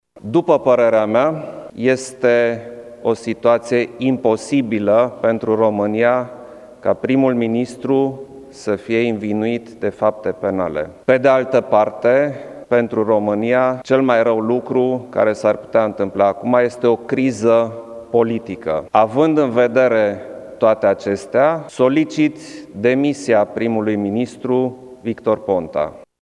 Într-o scurtă declarație, Klaus Iohannis spune că este nevoit să facă acest lucru pentru evitarea unei situații de criză, întrucât DNA a anunțat că premierul are calitatea de învinuit.